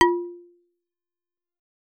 Xyl_FSharp2.L.wav